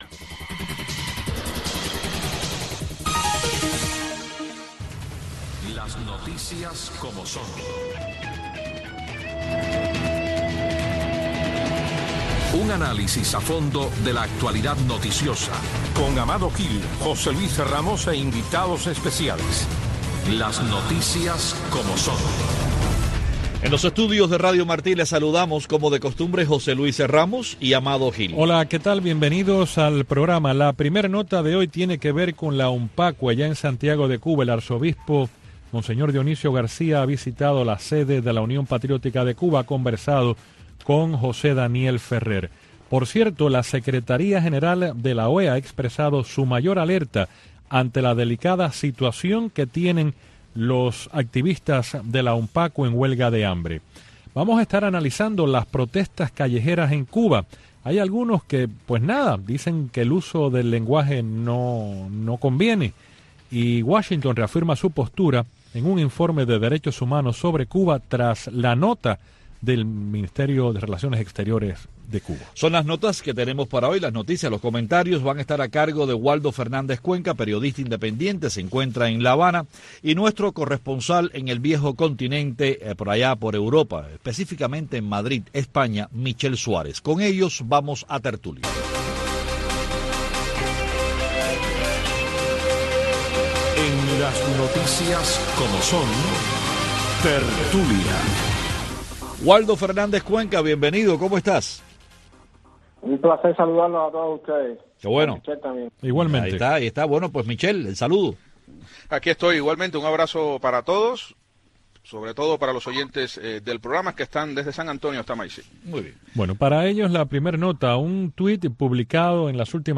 Una discusión dinámica y a fondo de las principales noticias del acontecer diario de Cuba y el mundo, con la conducción de los periodistas